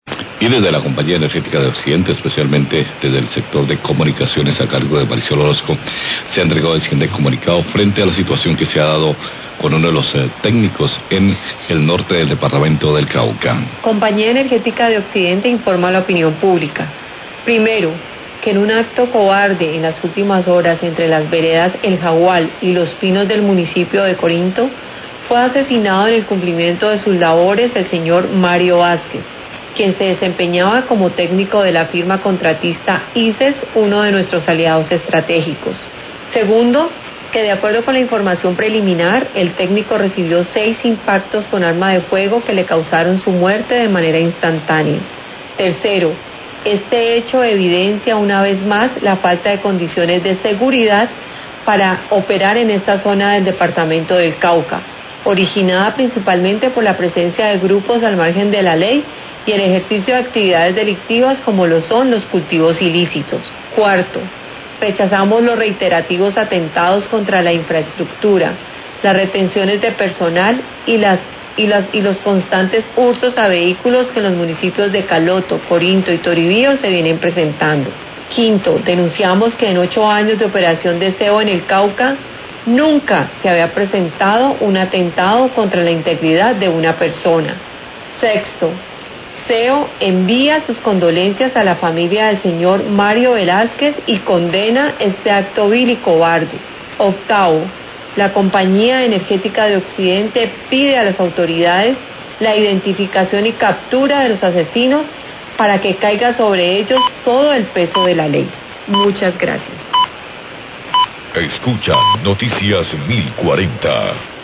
Radio
comunicado de prensa